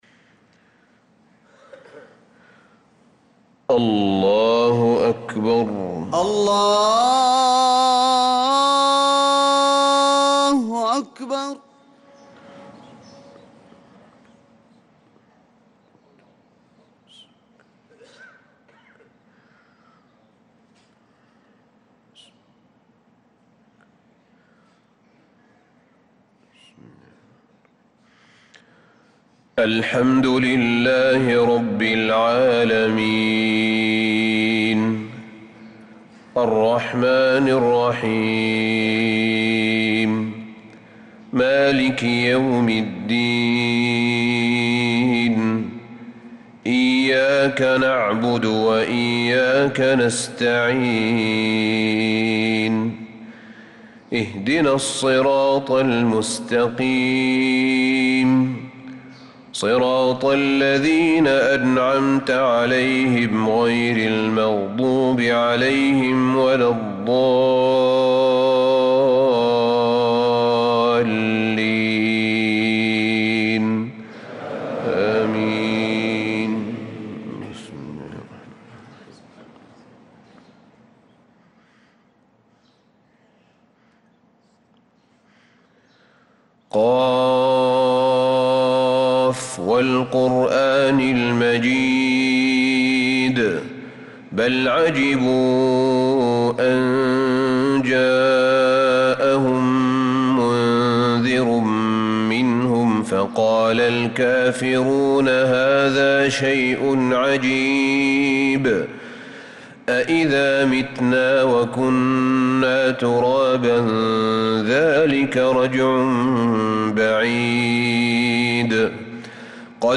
صلاة الفجر للقارئ أحمد بن طالب حميد 15 رجب 1446 هـ
تِلَاوَات الْحَرَمَيْن .